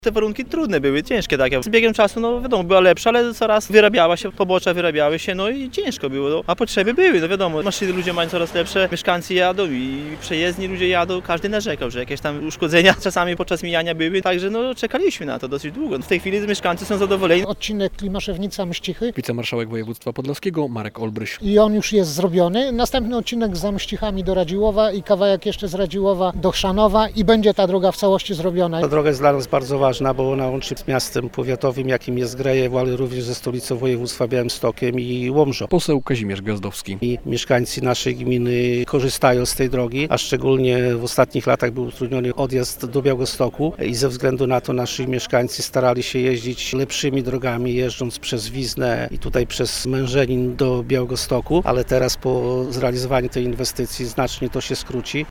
Oddano do użytku wyremontowany odcinek drogi z Osowca do Przytuł - relacja